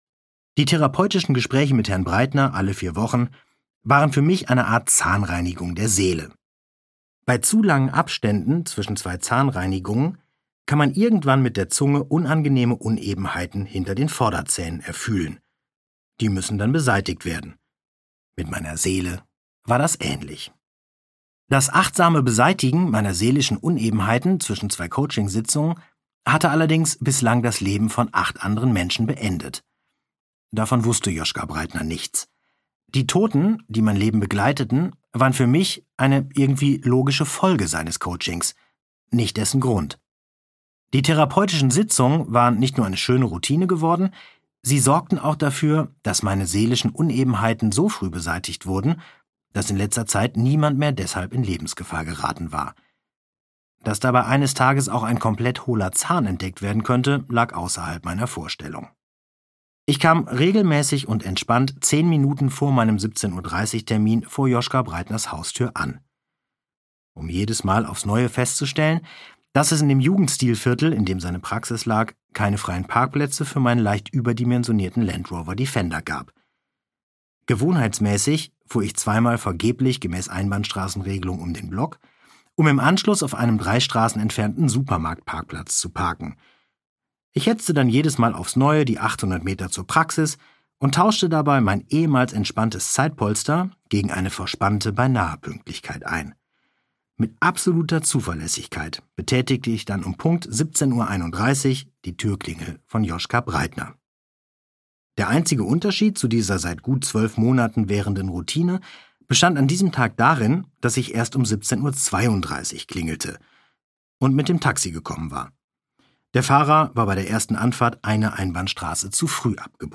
Achtsam morden am Rande der Welt (DE) audiokniha
Ukázka z knihy
• InterpretKarsten Dusse